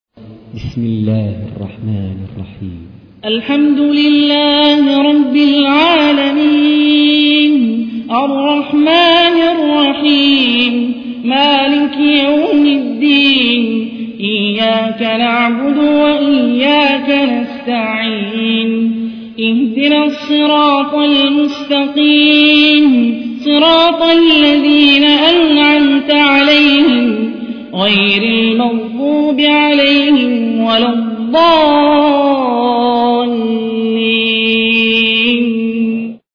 تحميل : 1. سورة الفاتحة / القارئ هاني الرفاعي / القرآن الكريم / موقع يا حسين